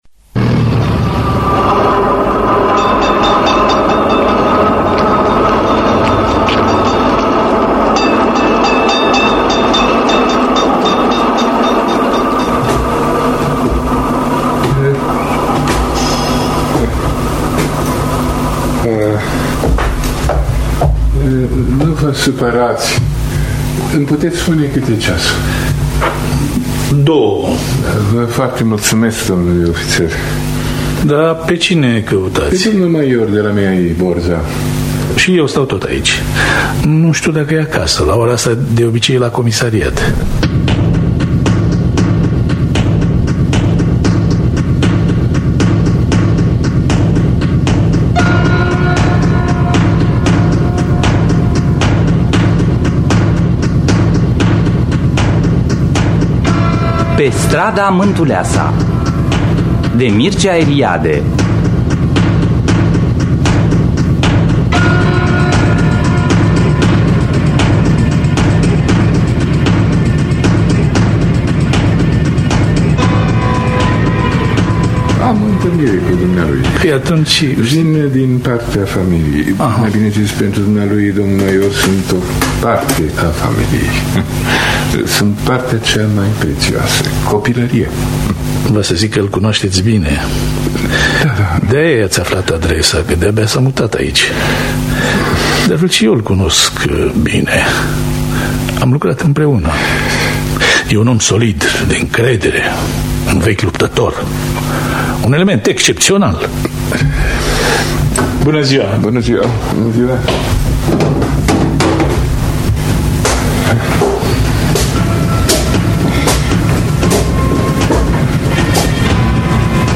Înregistrare din anul 1992 (24 aprilie).